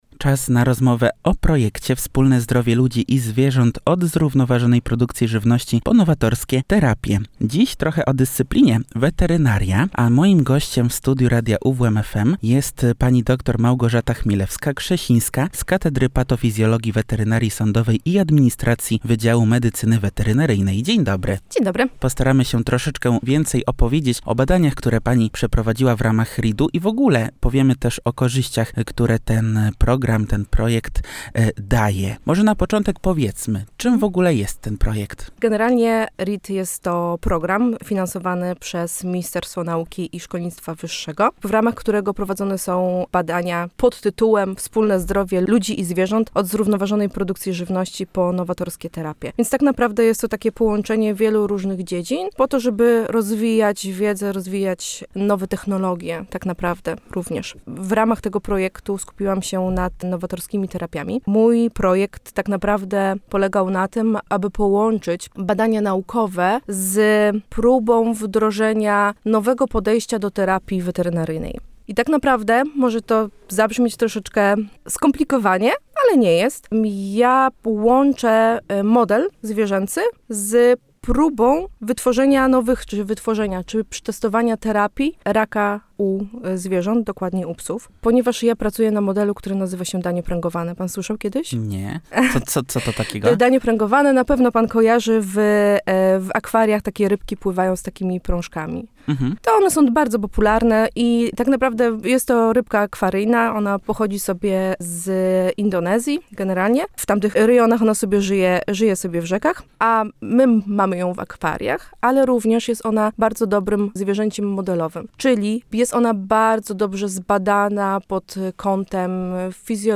W naszym studiu
w rozmowie